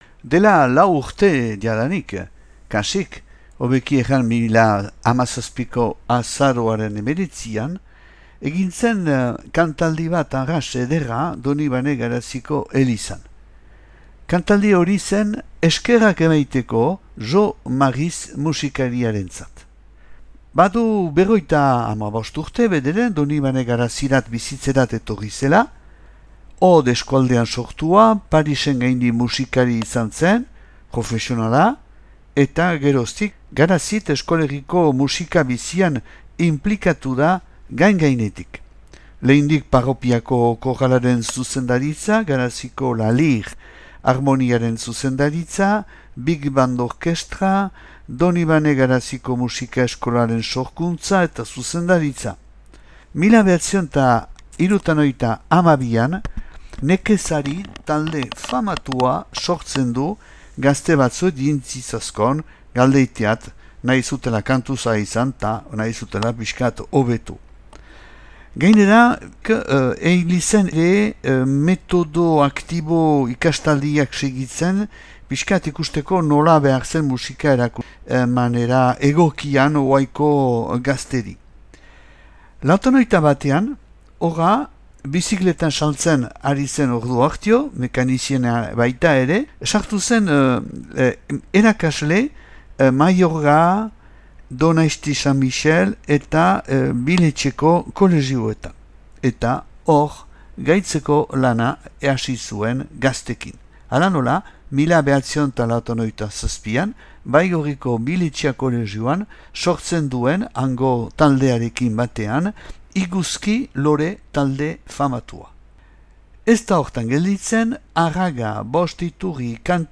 » kantaldia
Donibane Garaziko elizan 2017. azaroaren 19an.